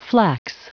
Prononciation du mot flax en anglais (fichier audio)
Prononciation du mot : flax